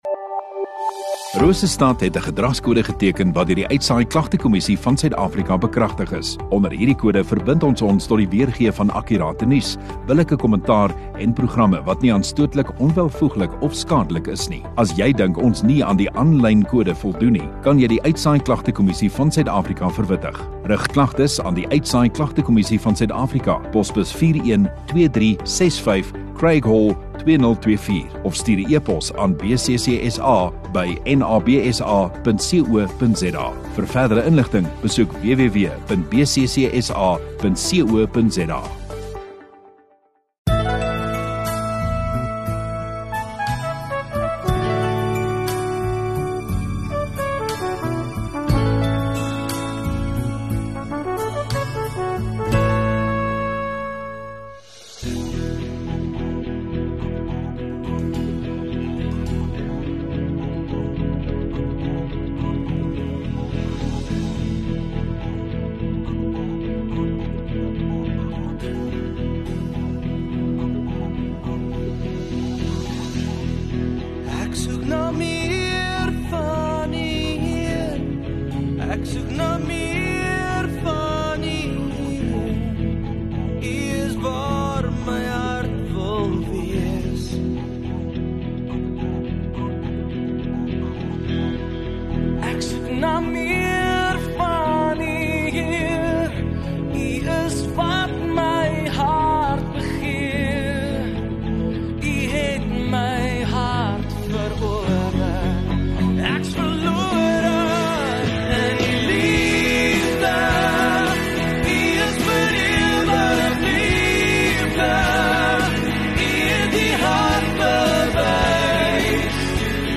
7 Sep Sondagaand Erediens